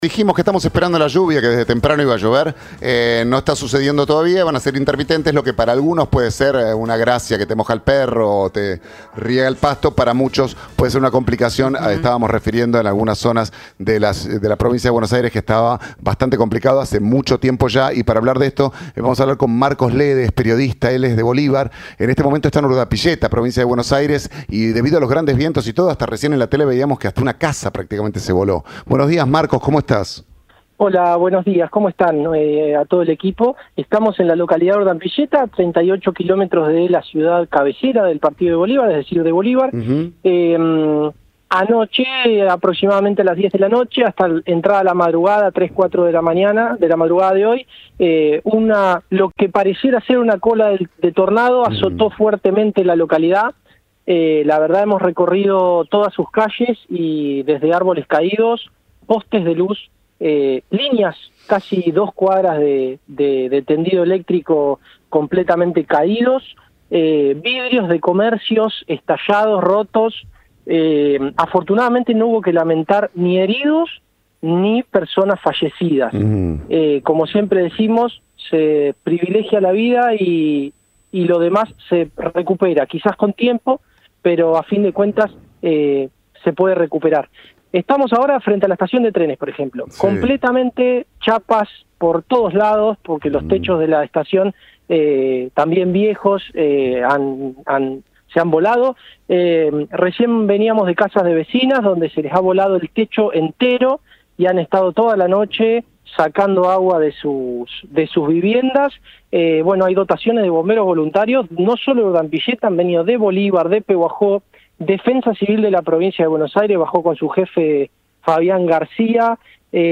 Entrevista a Luciano Bugallo El campo busca revertir en la Legislatura bonaerense la suba impositiva